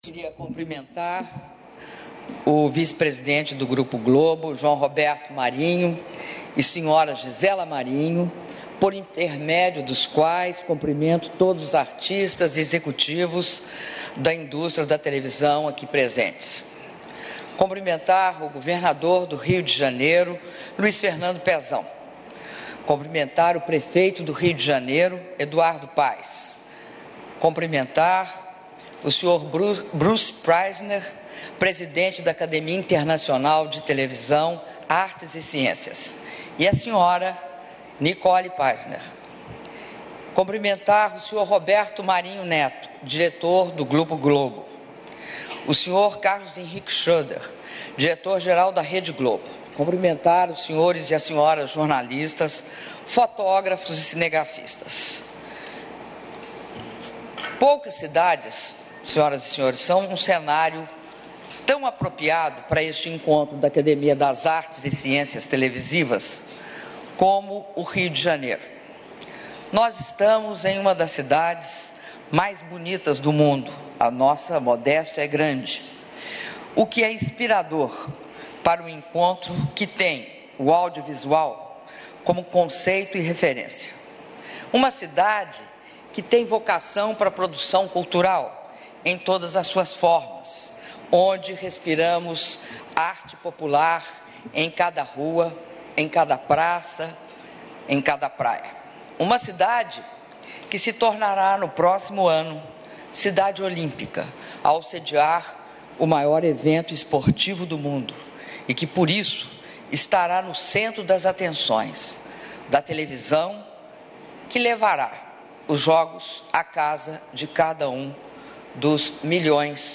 Áudio do discurso da presidenta da República, Dilma Rousseff, durante a abertura do Dia Internacional da Academia das Artes e Ciências Televisivas - Rio de Janeiro/RJ (10min34s)